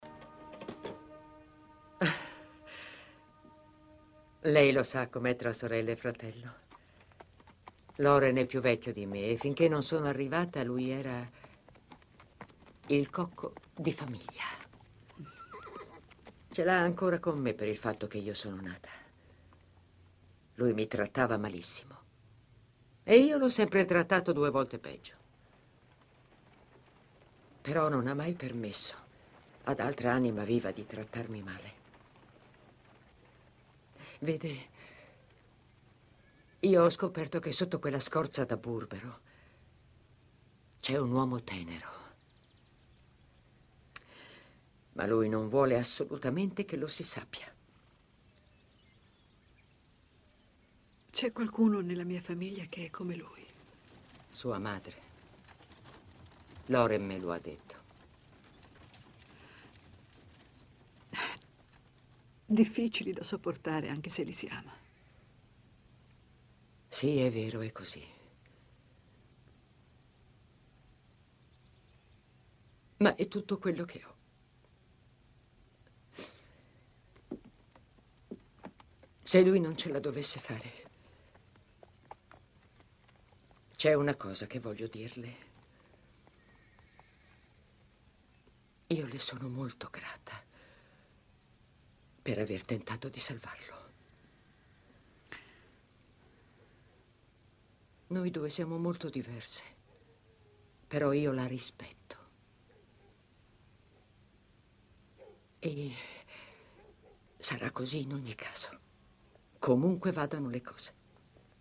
telefilm "La signora del West", in cui doppia Gail Strickland.